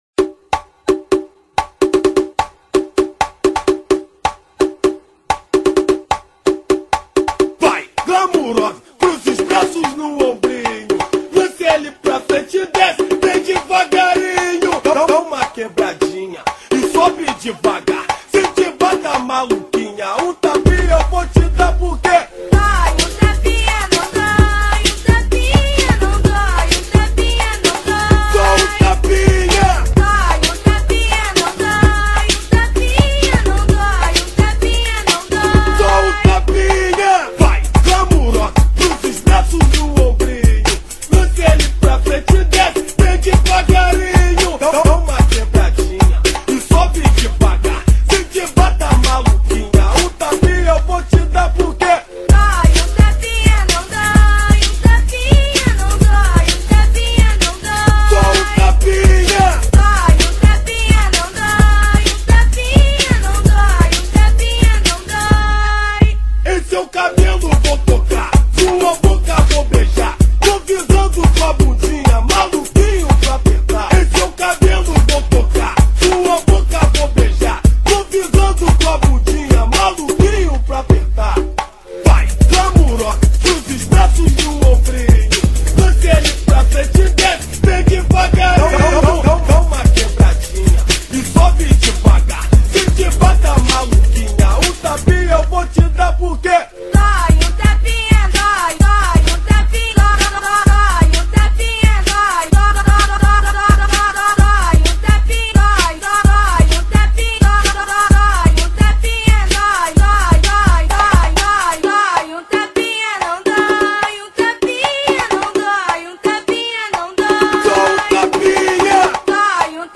Funk Para Ouvir: Clik na Musica.